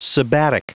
Prononciation du mot sabbatic en anglais (fichier audio)
Prononciation du mot : sabbatic